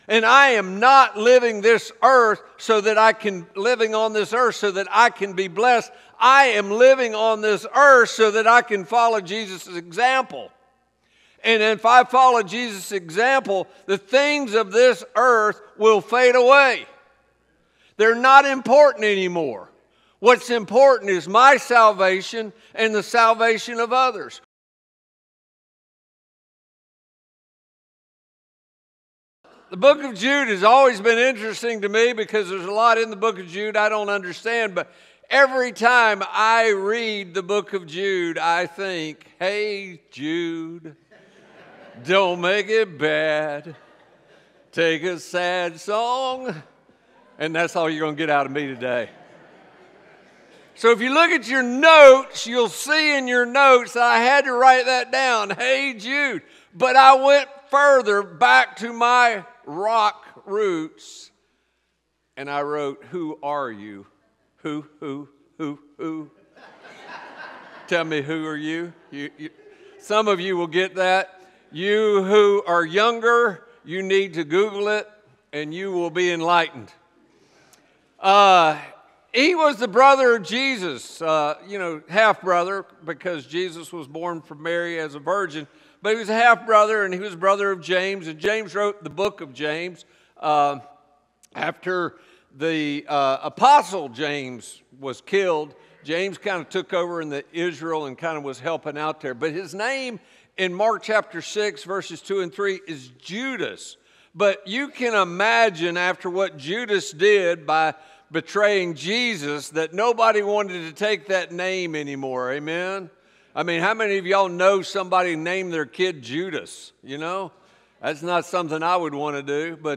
Recording from North Tampa Church of Christ in Lutz, Florida.